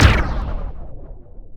polygon_explosion_antimatter.wav